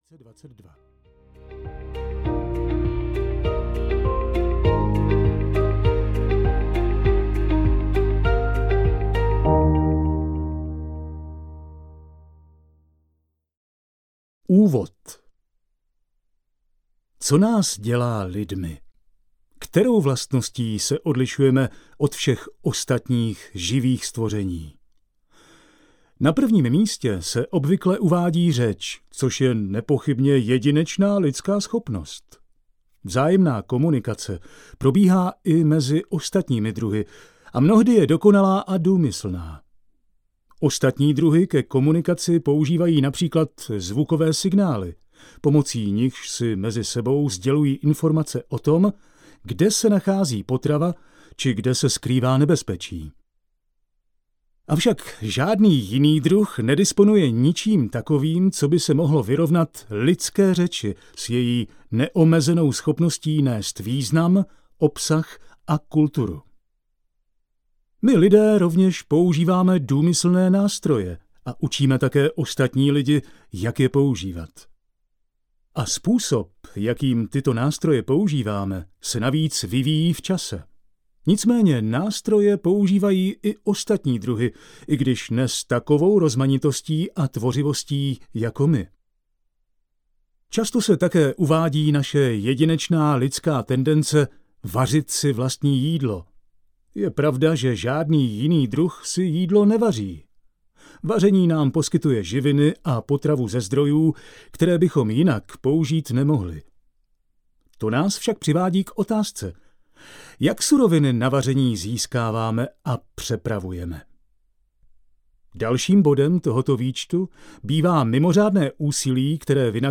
Proč chodíme audiokniha
Ukázka z knihy